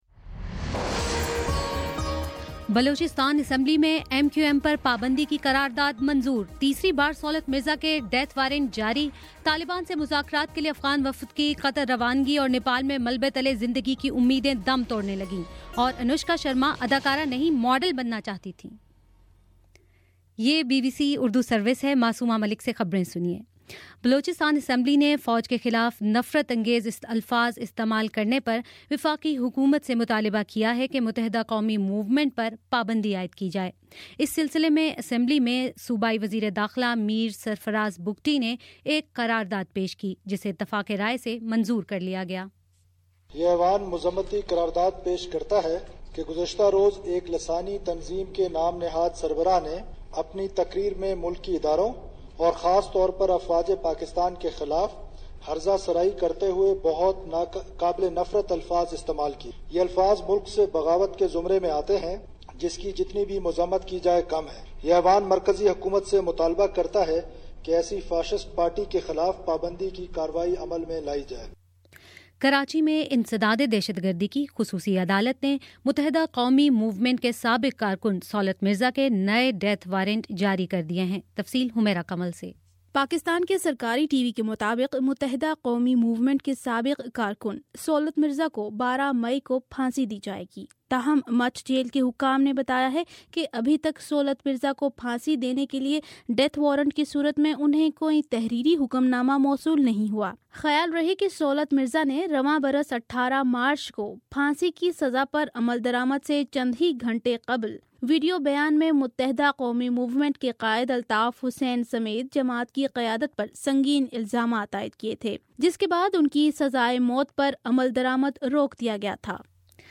مئی02: شام پانچ بجے کا نیوز بُلیٹن